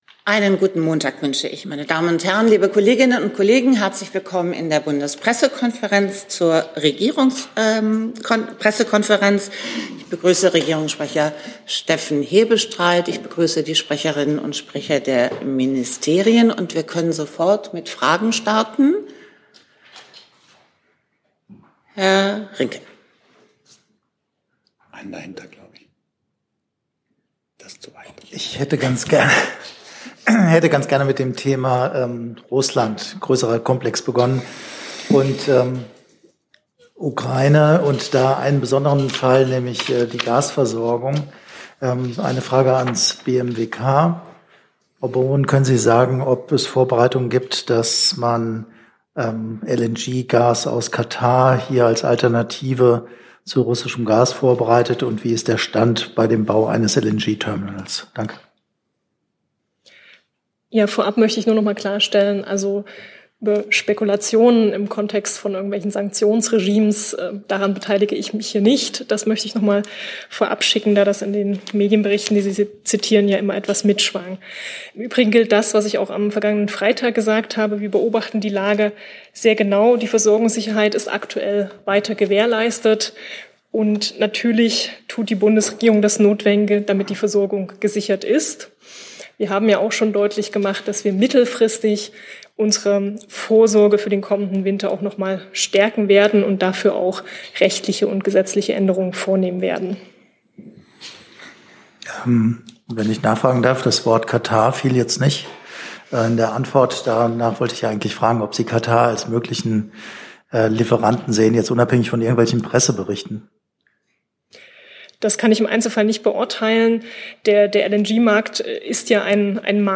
Regierungspressekonferenz in der BPK vom 31. Januar 2022 Ab sofort mit Kapitelmarken!